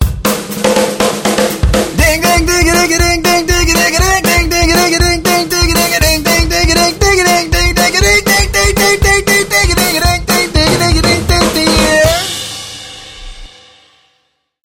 Ding Drum
ding sound effect free sound royalty free Sound Effects